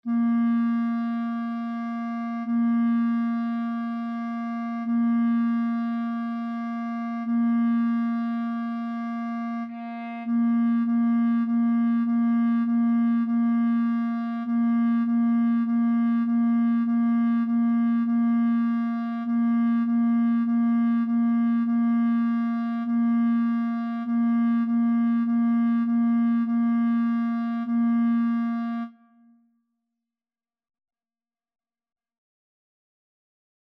4/4 (View more 4/4 Music)
Bb4-Bb4
Clarinet  (View more Beginners Clarinet Music)
Classical (View more Classical Clarinet Music)